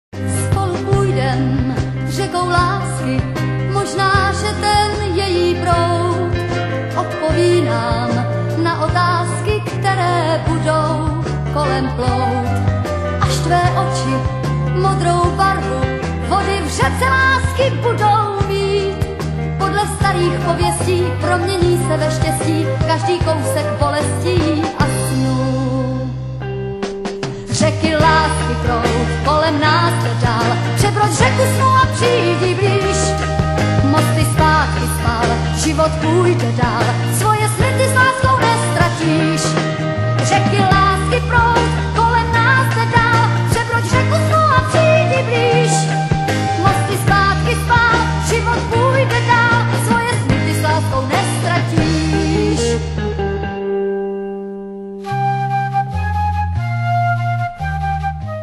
one of the most famous Czech woman pop singers ever.